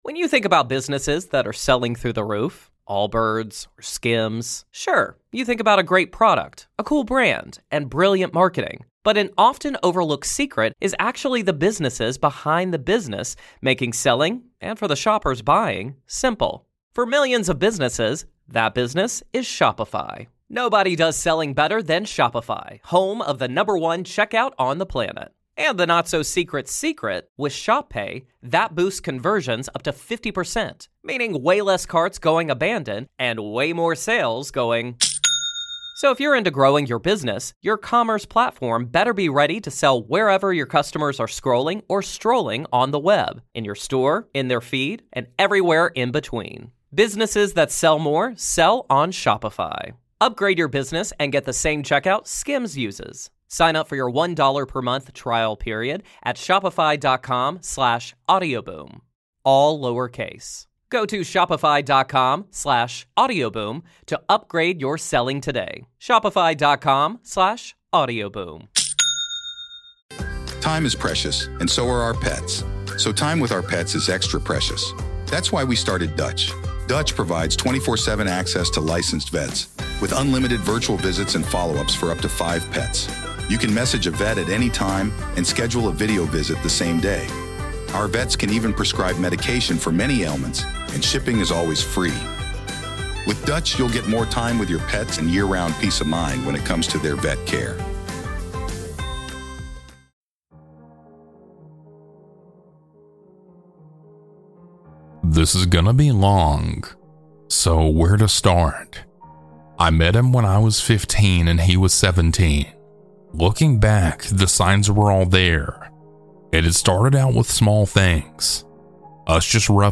- Anonymous Redditor Huge Thanks to these talented folks for their creepy music!